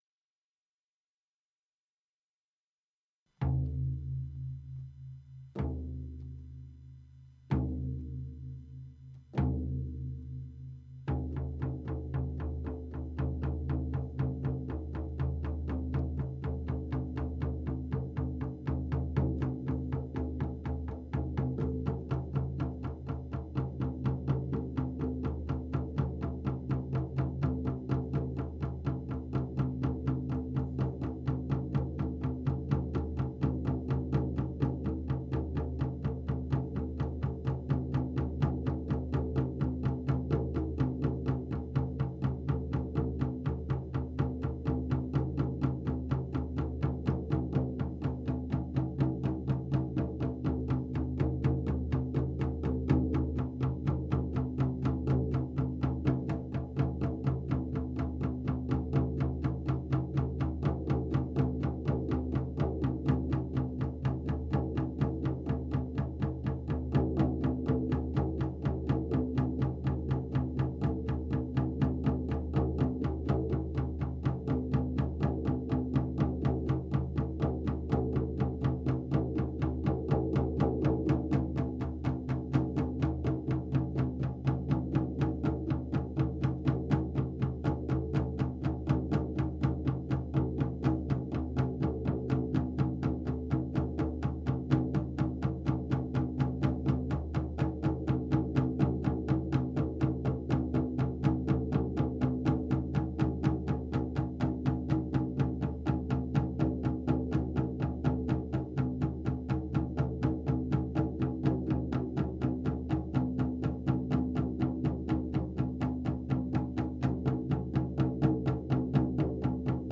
Three-Ravens-Drumming-15-minutes-v1.mp3